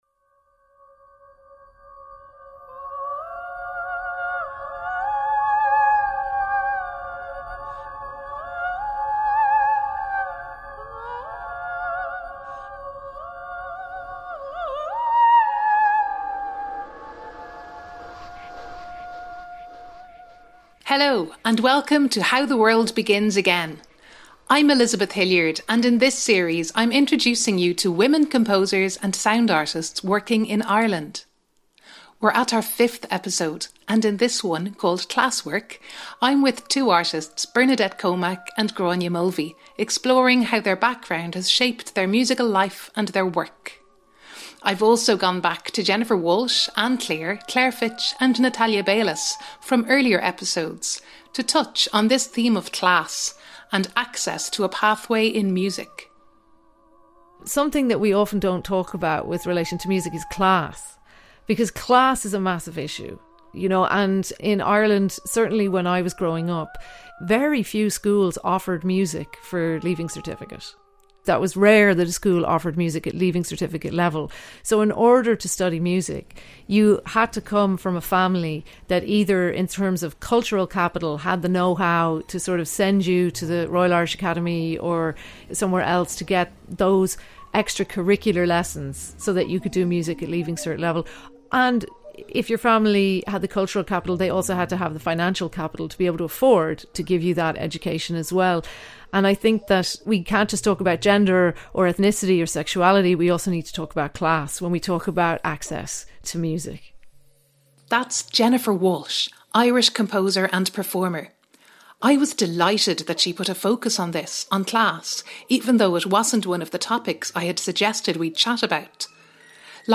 Poetry File